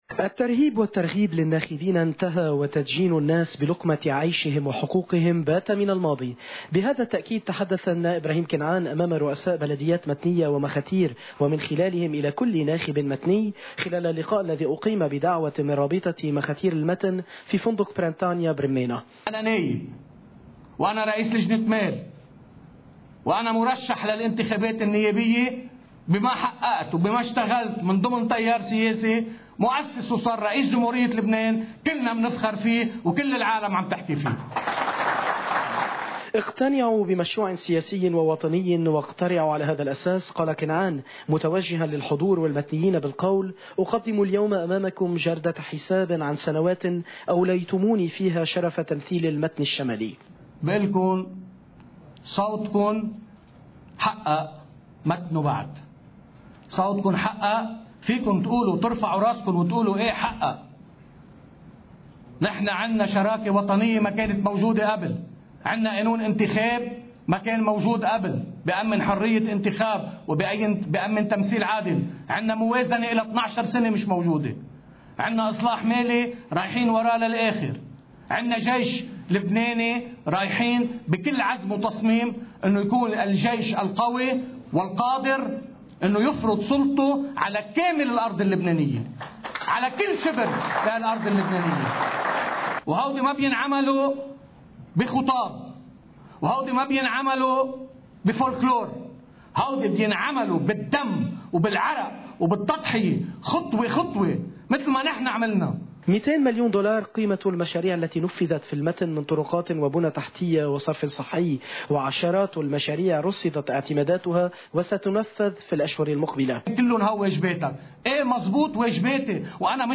الترهيب والترغيب للناخبين إنتهى، وتدجين الناس بلقمة عيشهم، وحقوقهم بات من الماضي.. بهذا التأكيد تحدث النائب ابراهيم كنعان، امام رؤساء بلديات متنية ومخاتير، ومن خلالهم الى كلّ ناخب متني، خلال اللقاء الذي أقيم بدعوة من رابطة مخاتير المتن، في فندق “برنتانيا” برمانا..
ابراهيم-كنعان-مخاتير-ورؤساء-بليات.mp3